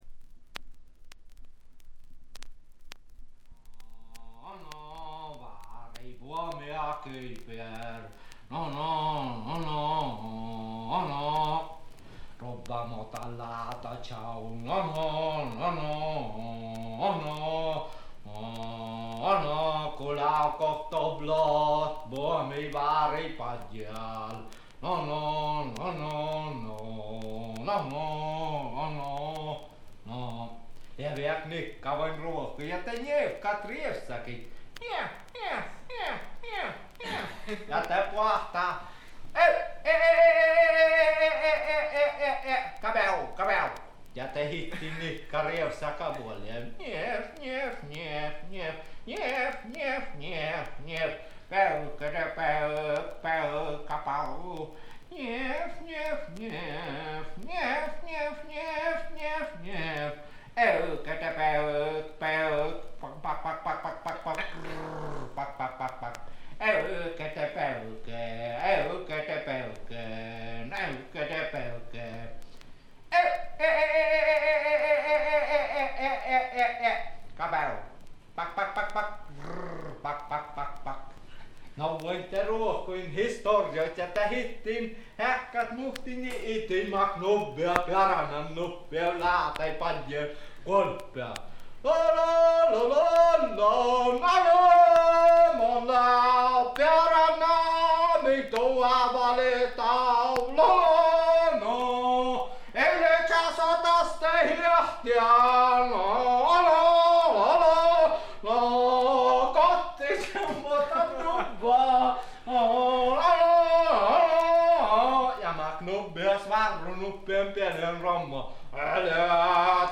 B1のみチリプチ多め大きめプツ音数回。
スウェーデンのトラッド・グループ
試聴曲は現品からの取り込み音源です。